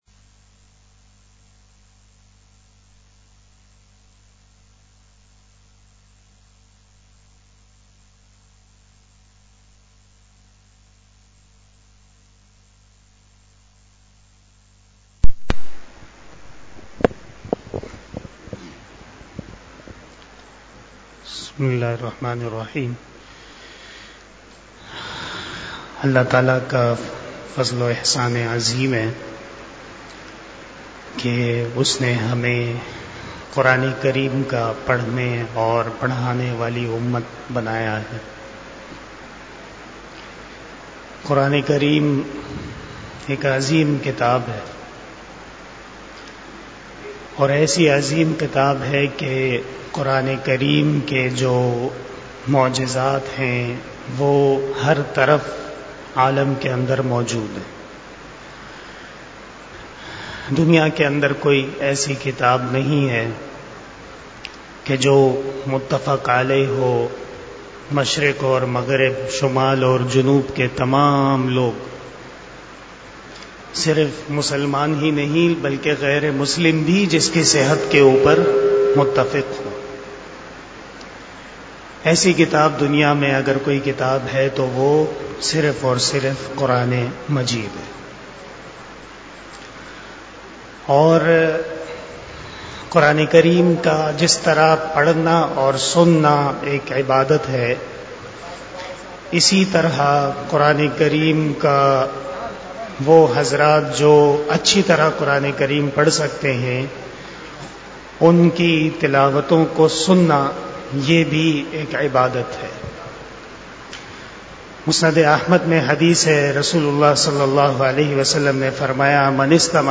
33 Shab E Jummah Bayan 03 October 2024 (29 Rabi ul Awwal 1446 HJ)